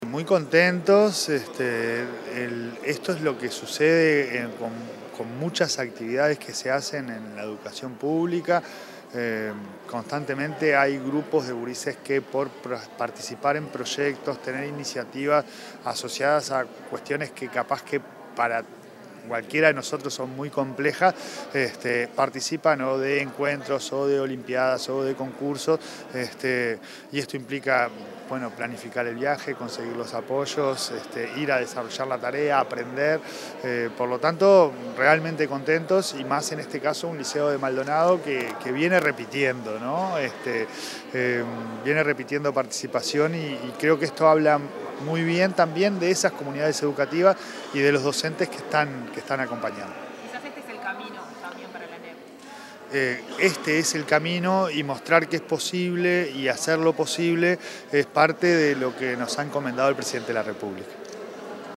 Declaraciones del presidente de la ANEP, Pablo Caggiani
Tras la ceremonia de reconocimiento a los estudiantes del liceo n.°4 de Maldonado que representarán a Uruguay en la competencia internacional organizada por la Administración Nacional de Aeronáutica y el Espacio (NASA), en Estados Unidos, el presidente de la Administración Nacional de Educación Pública (ANEP), Pablo Caggiani, dialogó con la prensa.